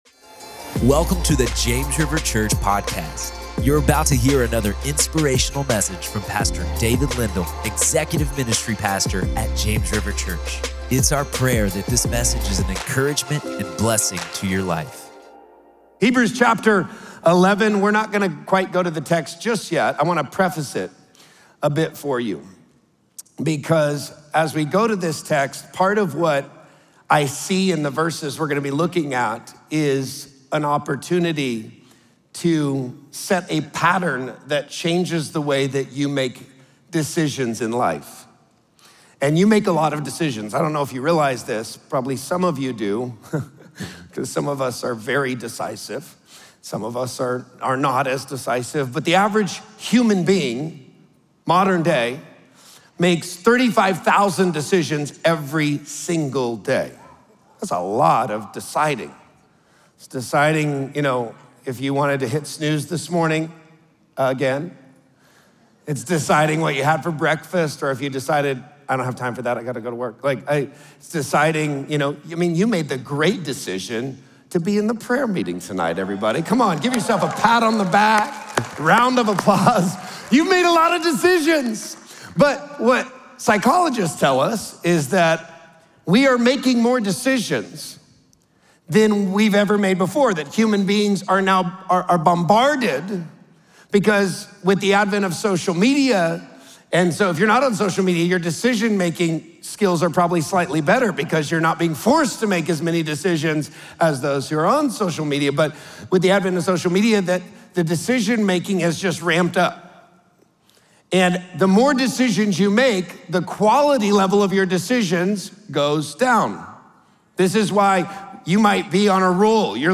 Faith Decides First | Prayer Meeting | James River Church